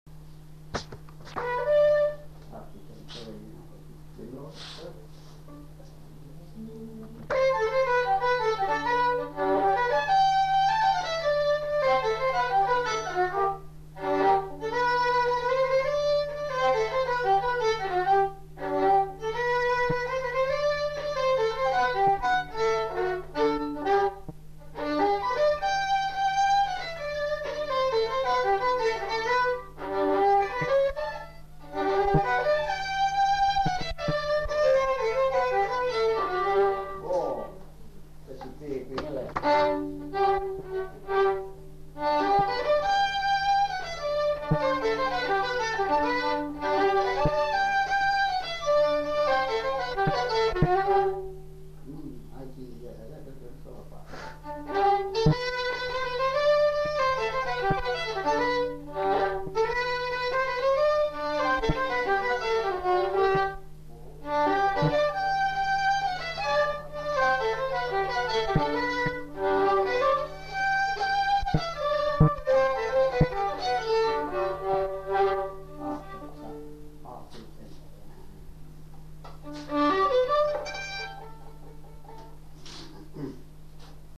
Rondeau
Aire culturelle : Lugues
Lieu : Saint-Michel-de-Castelnau
Genre : morceau instrumental
Instrument de musique : violon
Danse : rondeau
Notes consultables : L'interprète a du mal à se remémorer l'air.